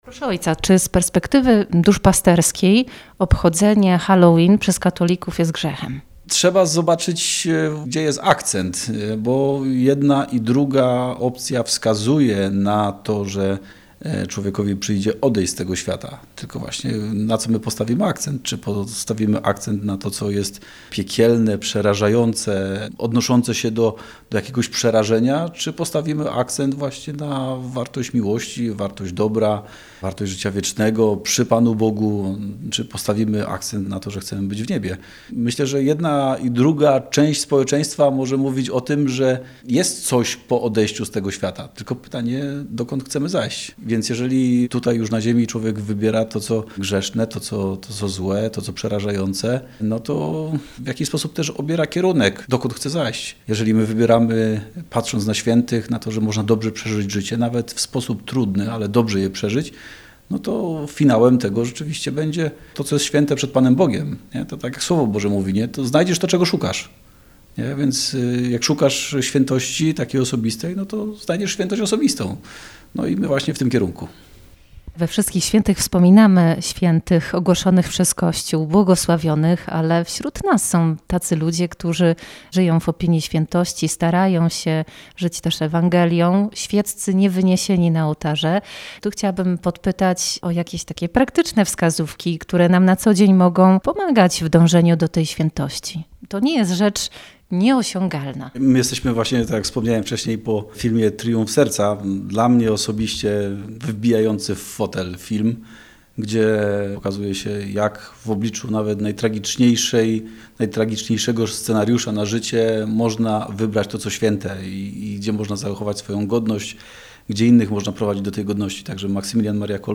Gościem programu jest